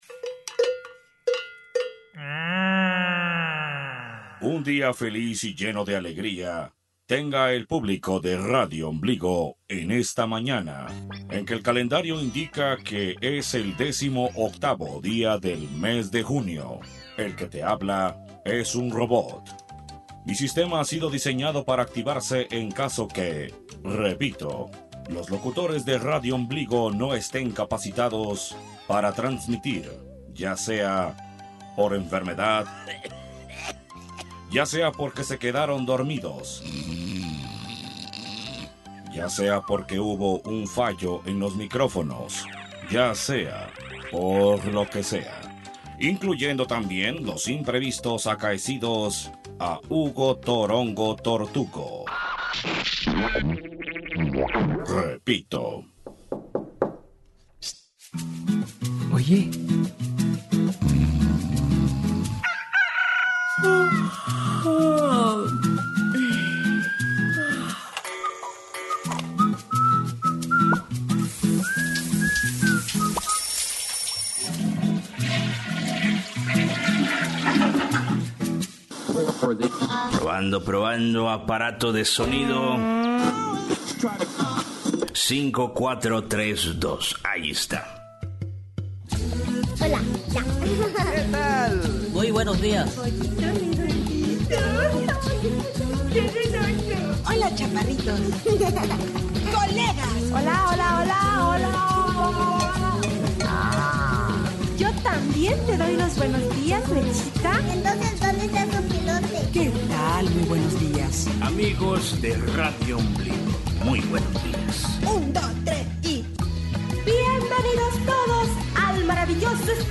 Hoy no escucharás a los locutores habituales, el robotito te dará la pauta para escuchar, ora un cuento, ora una canción, ora lo que sea.